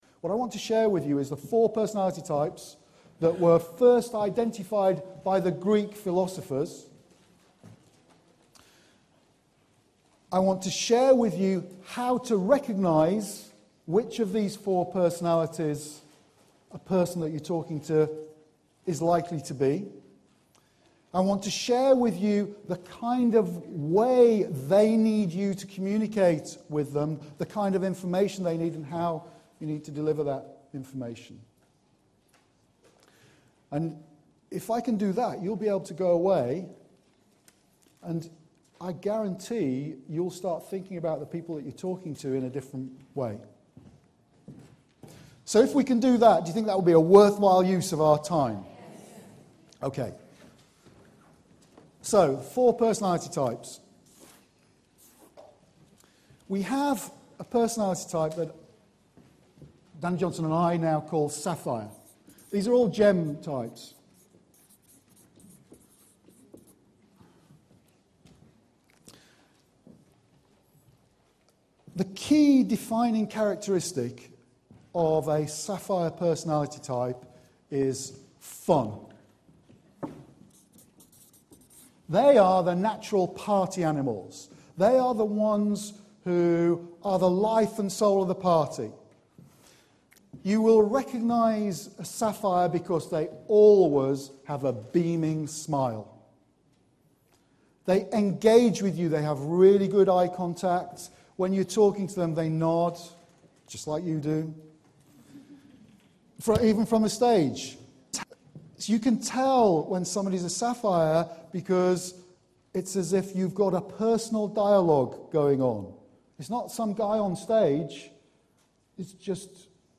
This training in Dundalk, Ireland, is all about how to recognise which way a person needs you to communicate with them so they can be comfortable with you and your message.